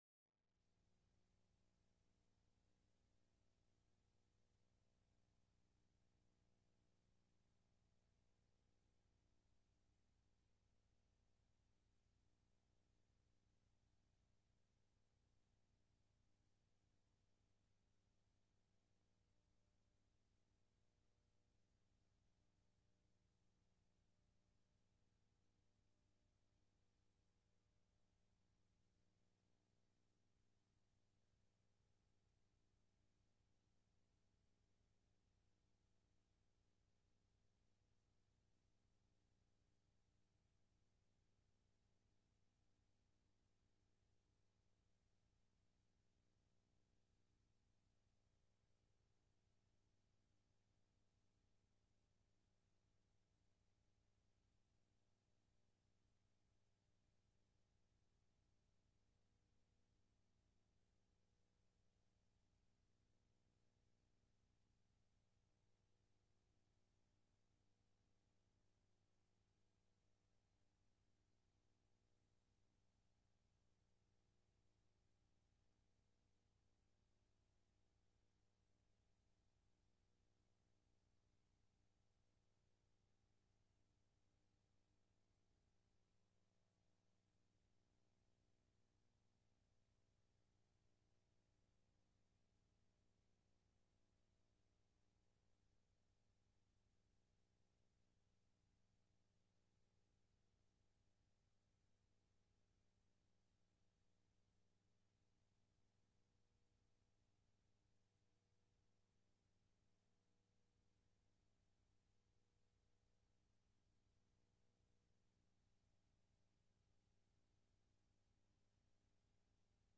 8_8-21-sermon.mp3